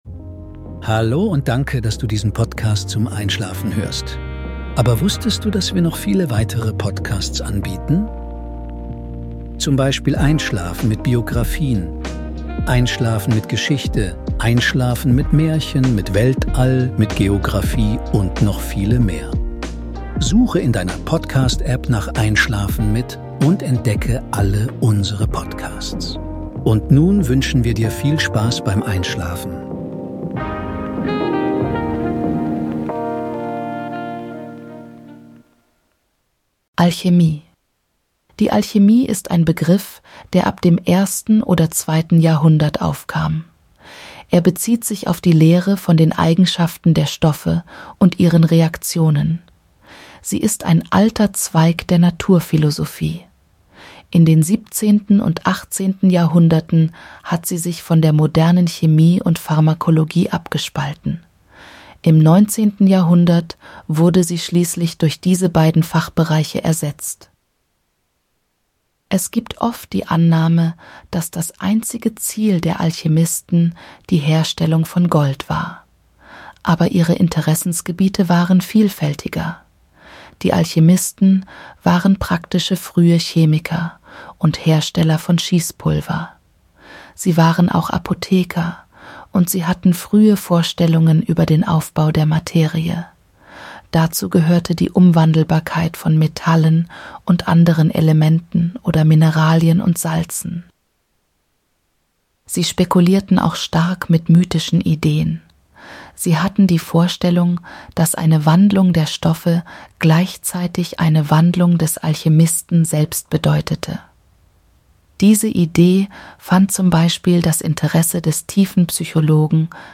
Wir lesen dir aus Wikipedia zum Einschlafen vor...